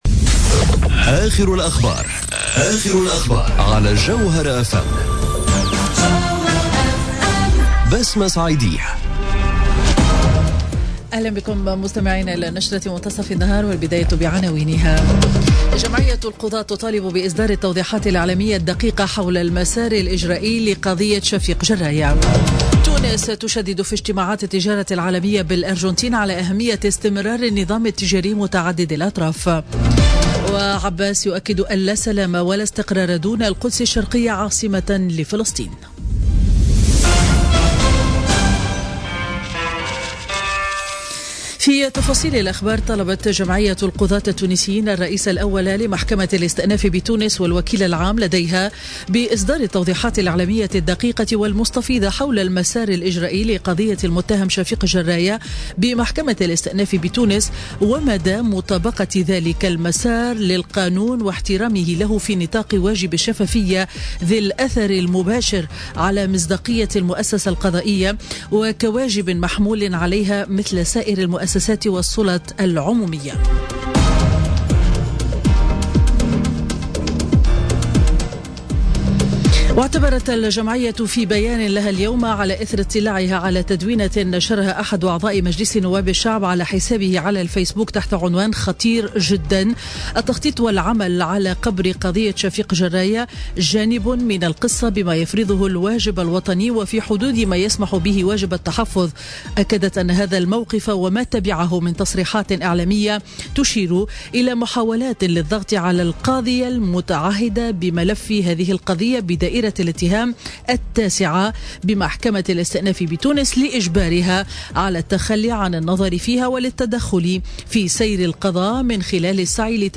Journal Info 12h00 du Mercredi 13 Décembre 2017